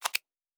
pgs/Assets/Audio/Sci-Fi Sounds/Interface/Click 13.wav
Click 13.wav